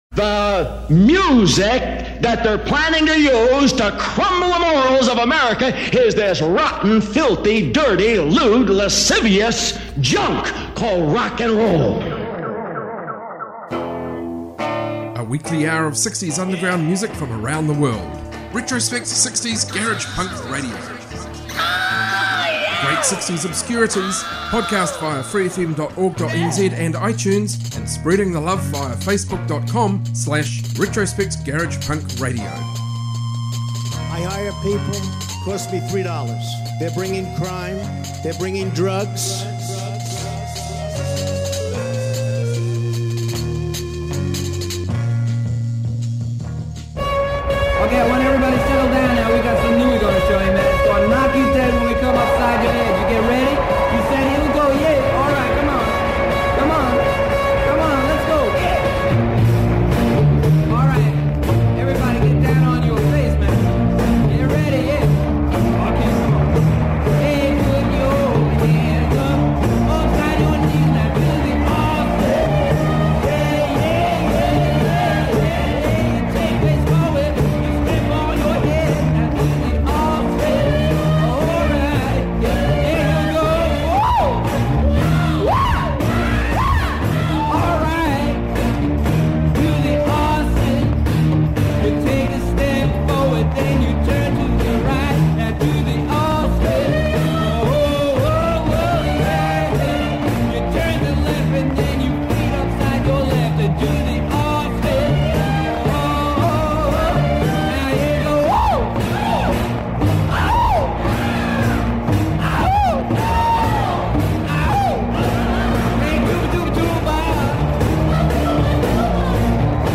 60s garage rock garage punk proto-punk freakbeat